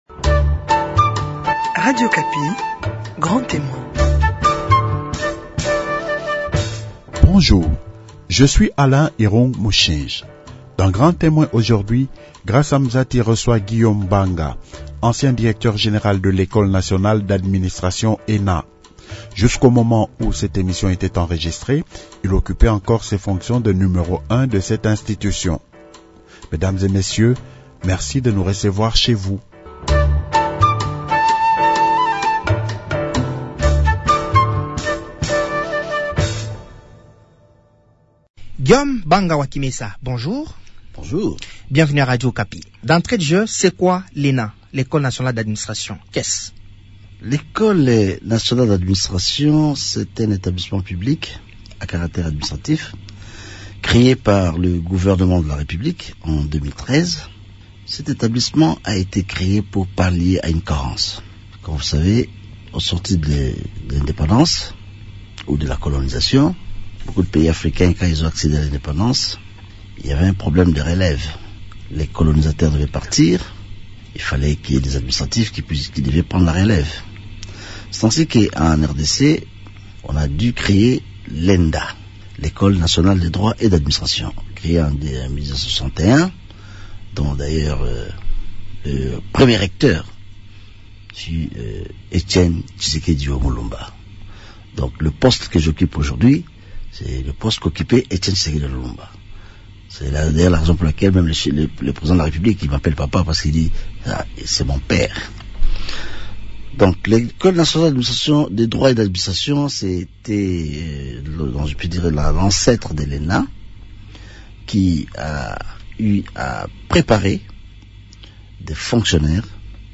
Jusqu’au moment où cette émission était enregistrée, il occupait encore ses fonctions de numéro 1 de cette institution.